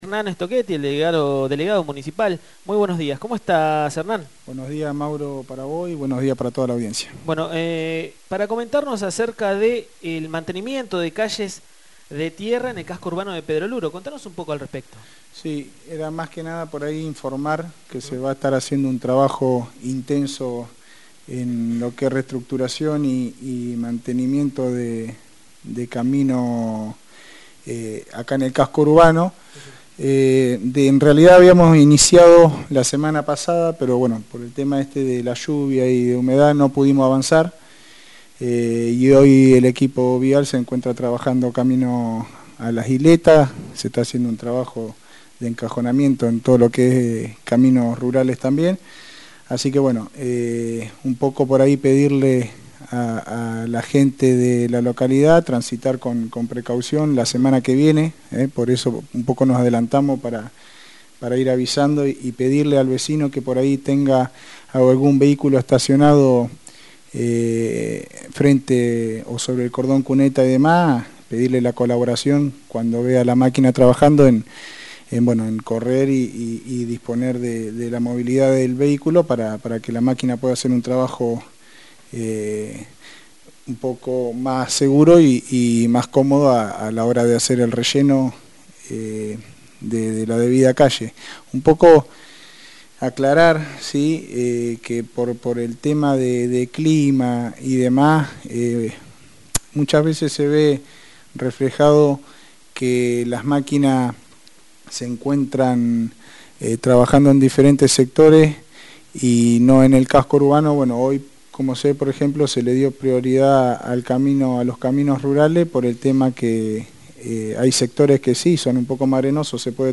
El Delegado Municipal Hernán Stochetti visitó los estudios de la ZFM para informar a la comunidad sobre los trabajos de reestructuración de calles que se están llevando a cabo debido a las fuertes lluvias que han afectado la localidad y la zona rural.